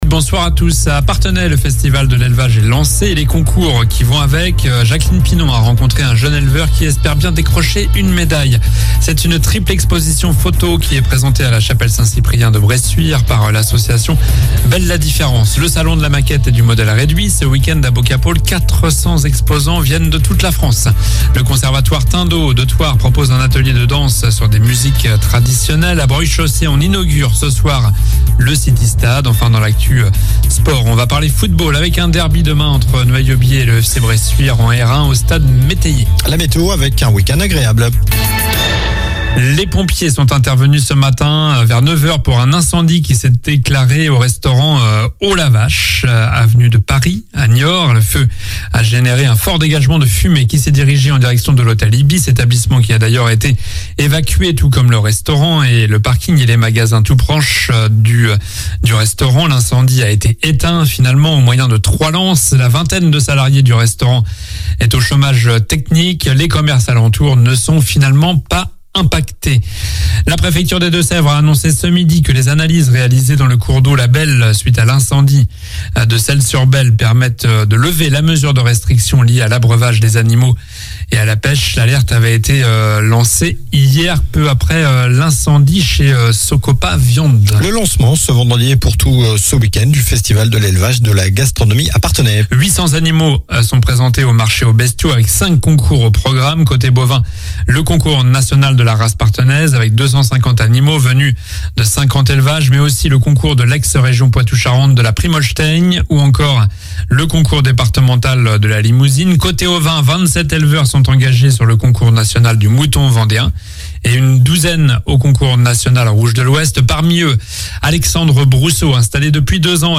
Journal du vendredi 22 septembre (soir)